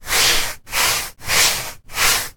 Звуки краски
4 мазка кистью на стене